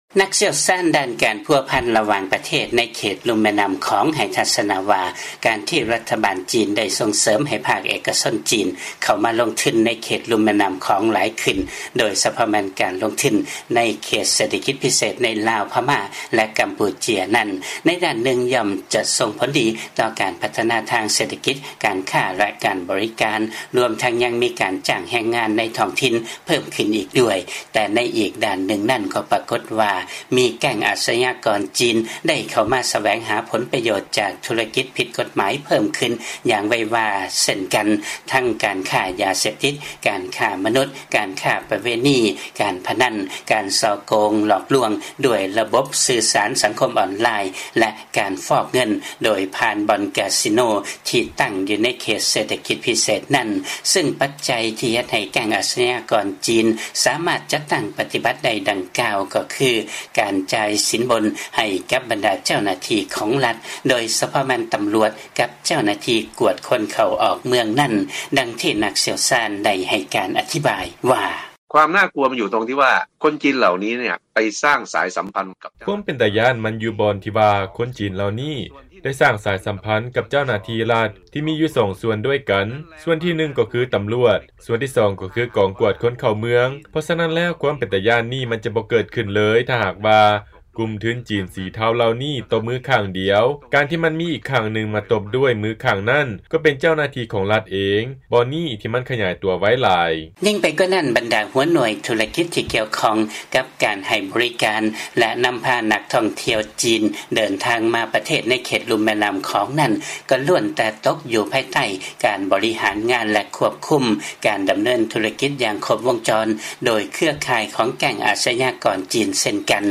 ຟັງລາຍງານ ແກັ່ງອາຊະຍາກອນ ຈີນ ມີອິດທິພົນໃນເຂດລຸ່ມແມ່ນ້ຳຂອງເພີ່ມຂຶ້ນ ດ້ວຍການຊື້ຈ້າງເຈົ້າໜ້າທີ່ລັດ